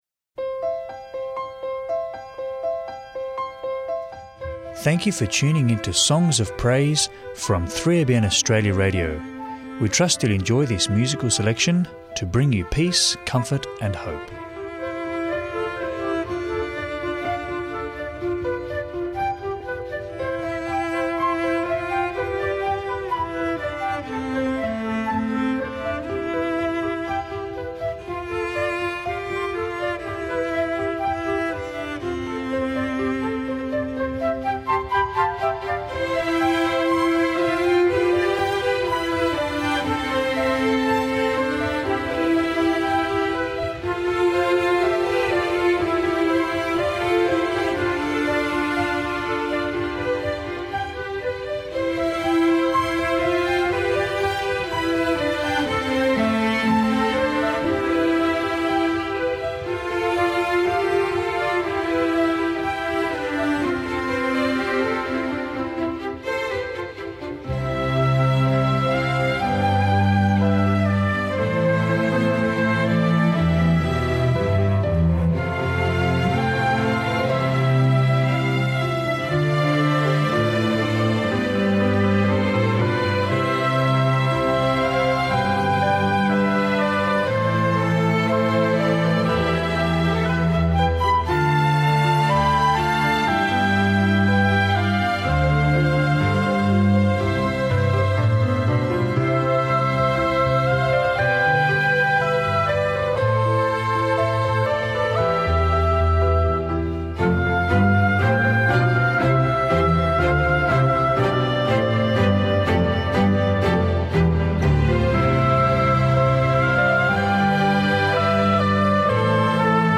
Enjoy 1 hour episodes of musical reflection to encourage, uplift and draw you into a closer relationship with our loving Saviour, Jesus Christ. Also featuring a short 3ABN Australia Radio Book Reading.